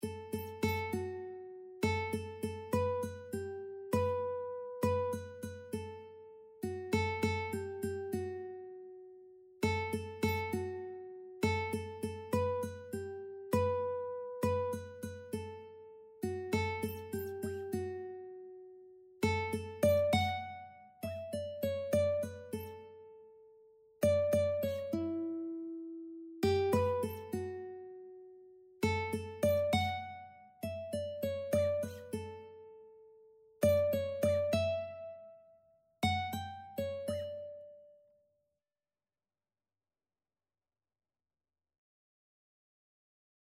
Christian
4/4 (View more 4/4 Music)